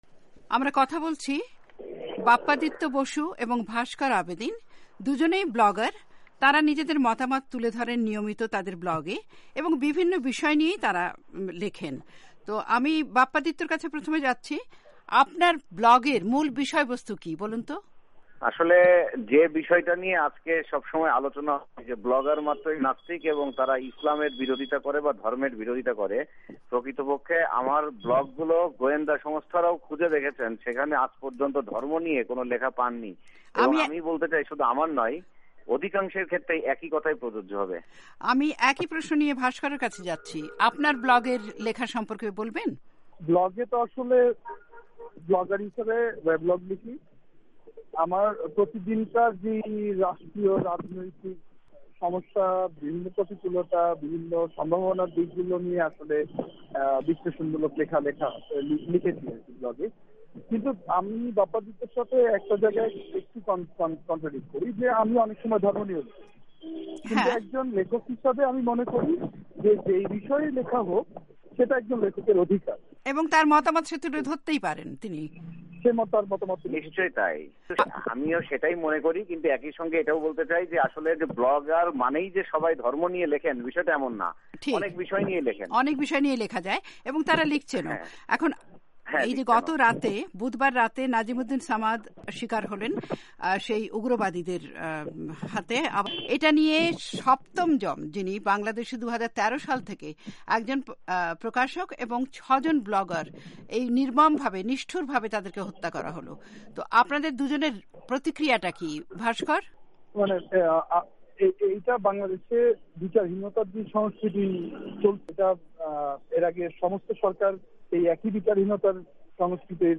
বাংলাদেশে ব্লগারদের ওপর নৃশংস আক্রমণ নিয়ে ওয়াশিংটন স্টুডিও থেকে টেলিফোনে ঢাকার দুজন ব্লগার